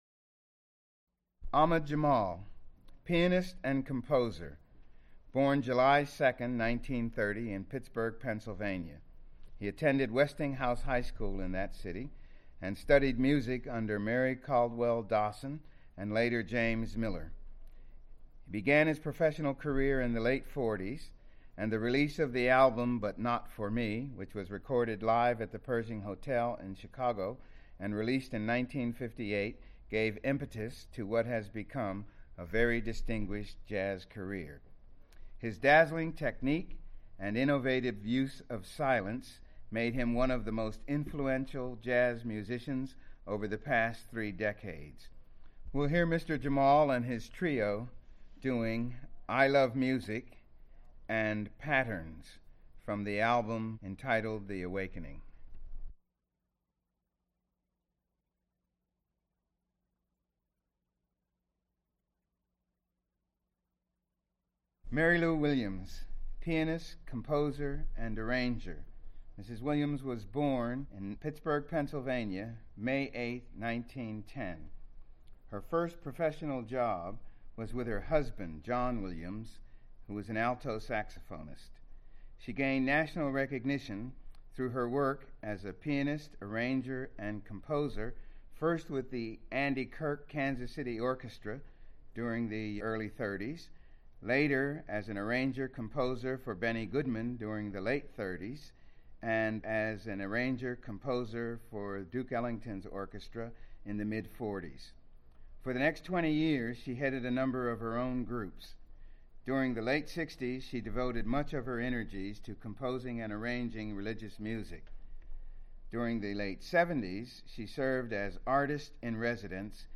Recorded live 1982?